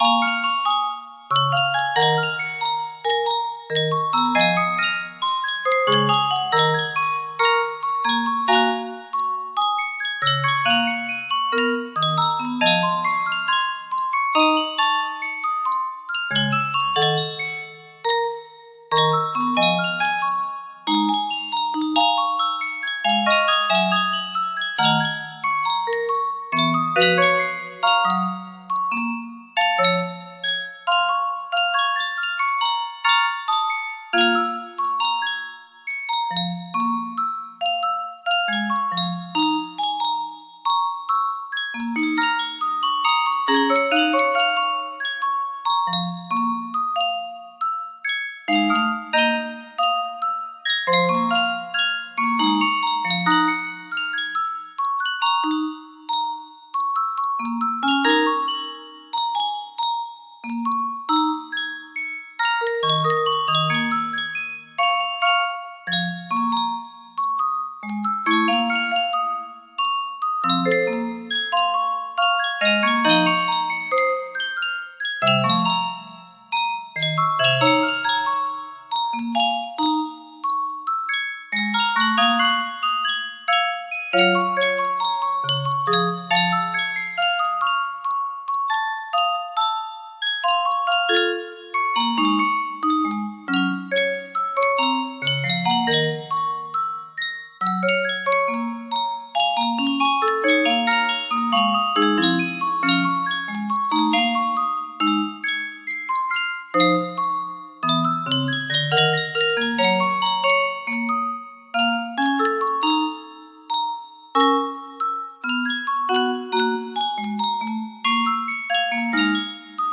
music box melody
Full range 60
69 BPM ofc lol
Just A Blues Derived, Original Jam Out.